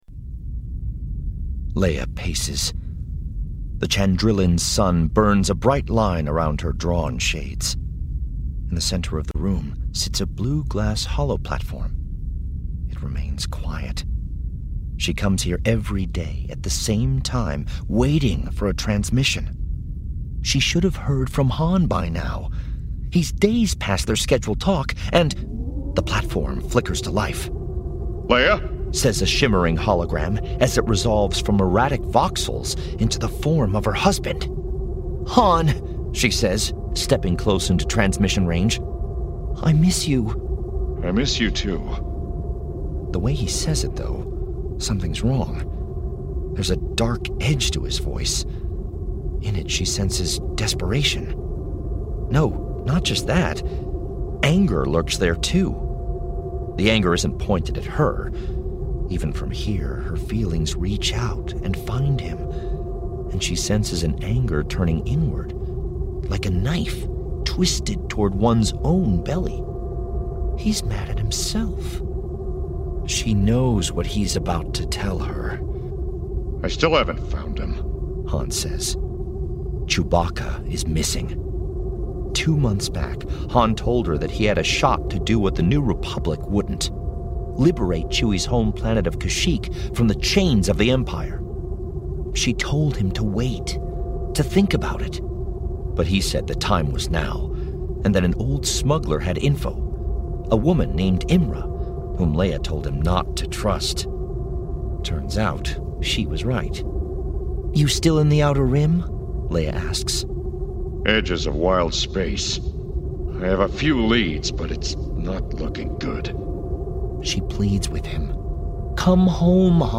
Star Wars: Aftermath Life Debt Audio book clip